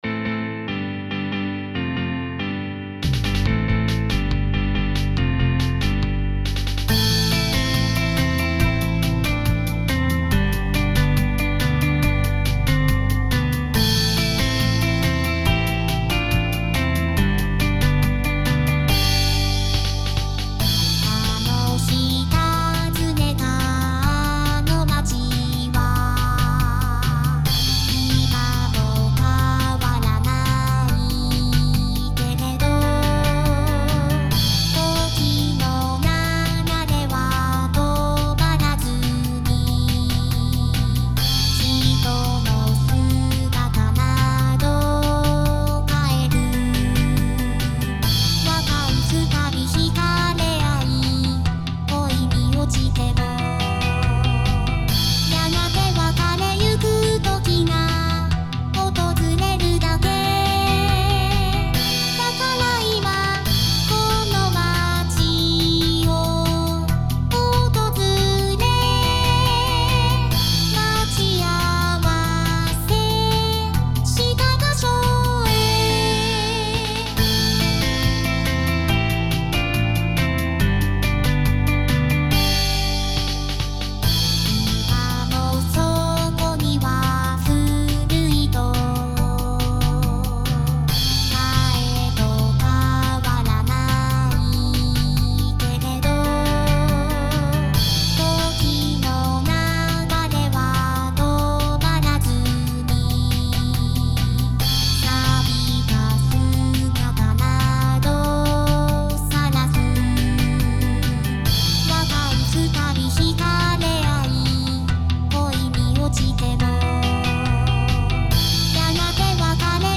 VSTi